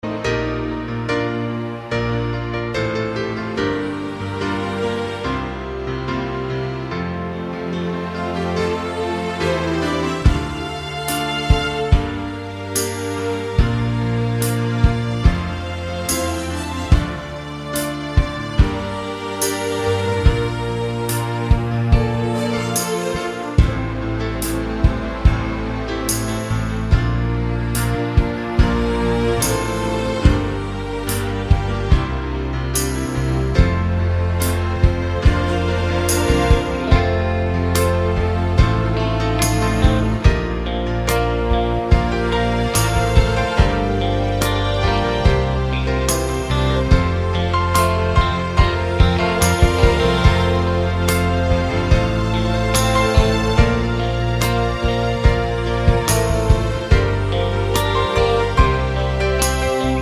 Short Version with No Backing Vocals Rock 3:54 Buy £1.50